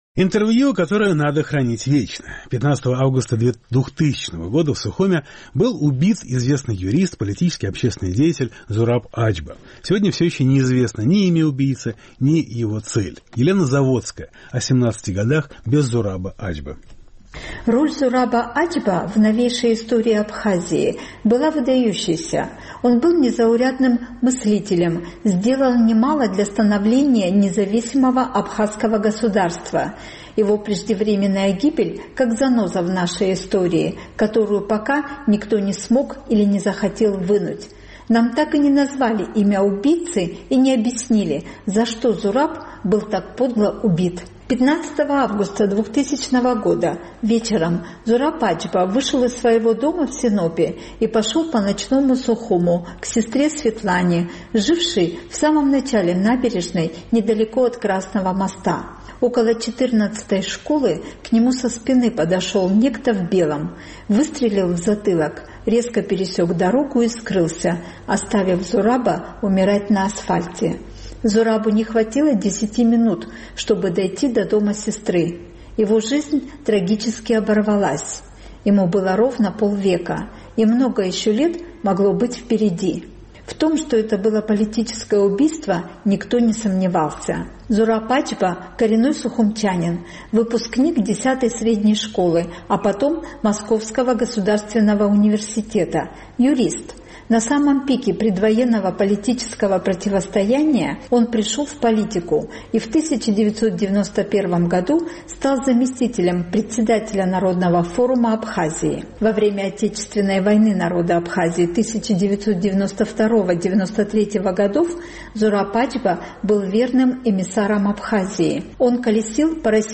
Я нашла на просторах интернета интервью 1992 года, в нем голос Зураба звучит так, как будто все эти семнадцать лет он был с нами.